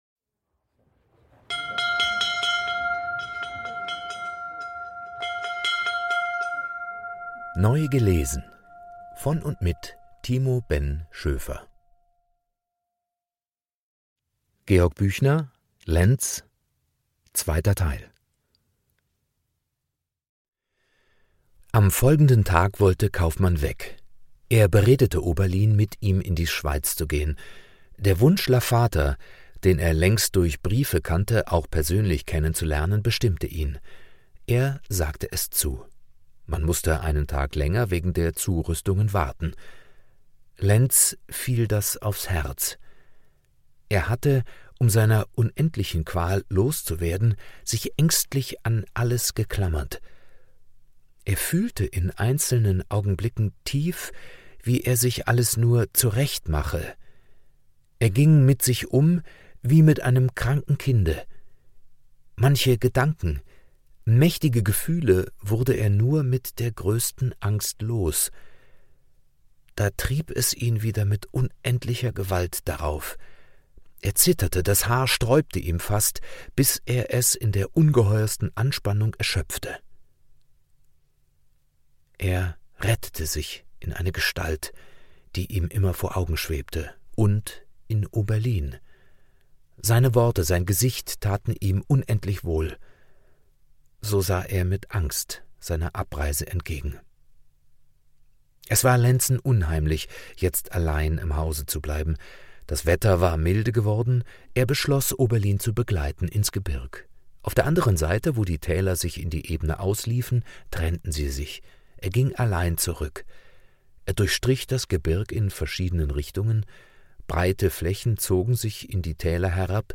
Literatur des 19.Jahrhunderts,vorgelesen